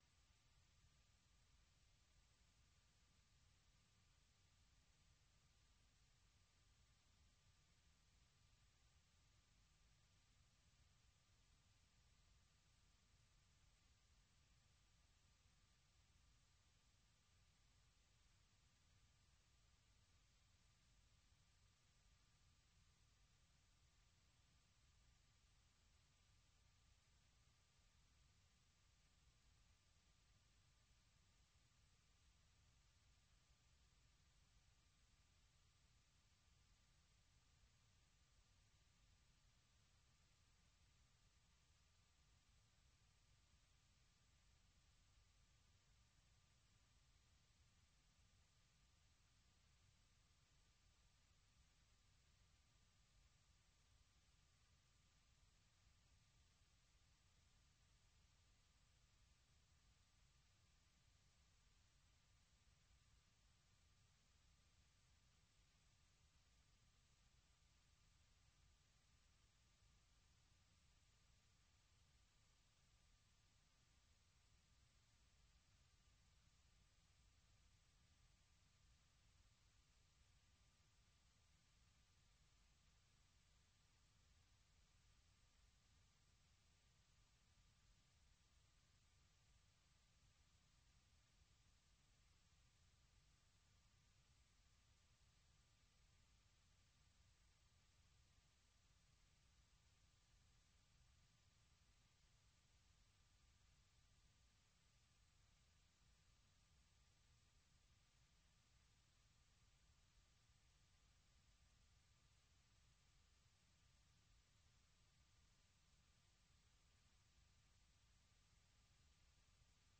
Balade musicale dans le monde entier, rions un peu avec de la comédie, interviews des divers artistes avec